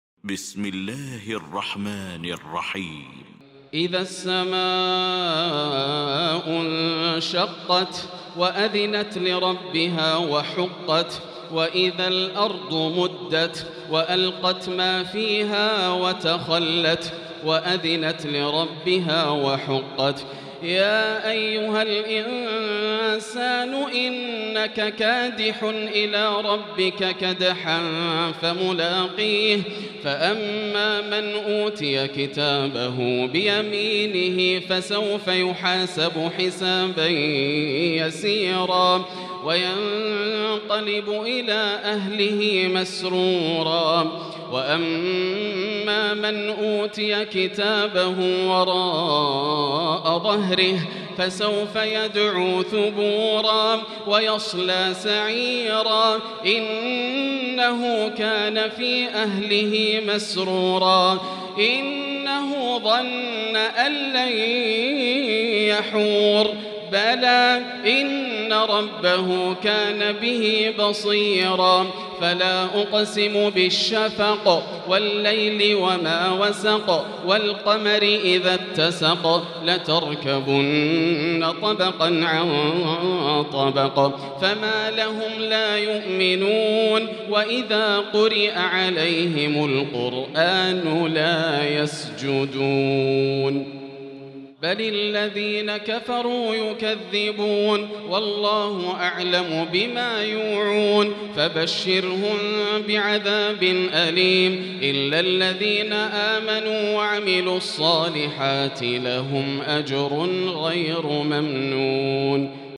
المكان: المسجد الحرام الشيخ: فضيلة الشيخ ياسر الدوسري فضيلة الشيخ ياسر الدوسري الانشقاق